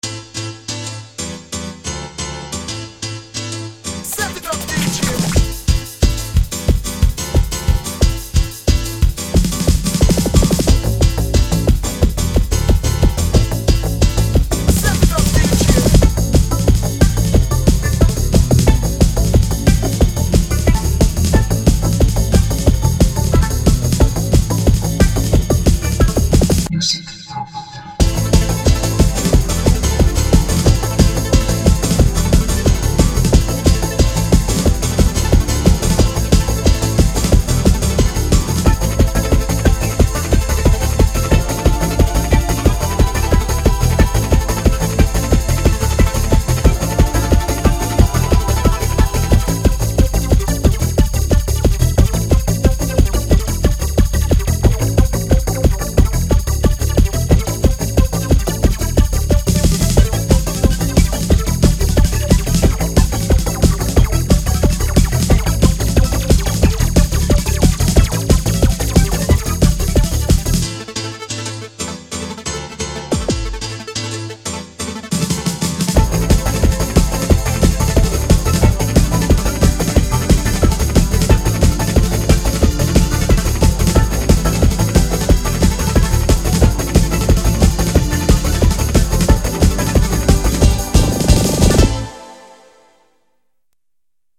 BPM189--1
Audio QualityPerfect (High Quality)